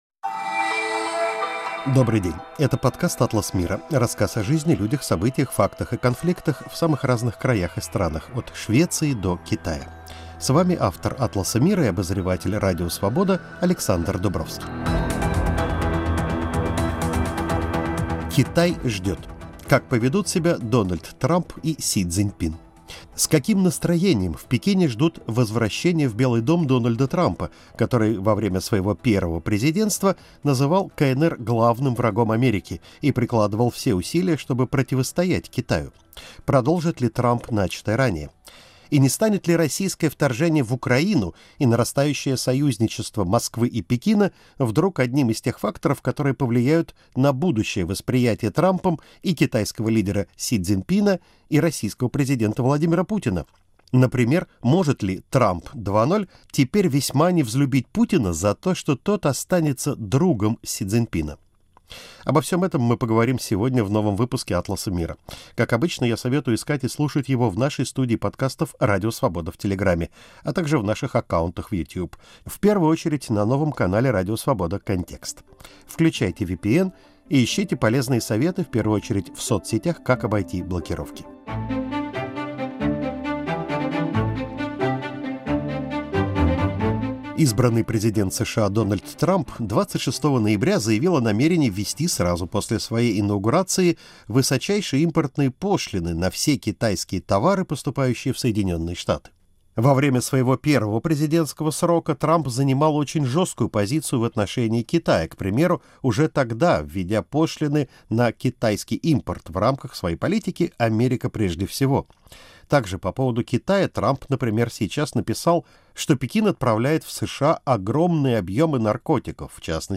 Каждую неделю журналисты-международники беседуют о жизни, людях, событиях, фактах и конфликтах за пределами России и США.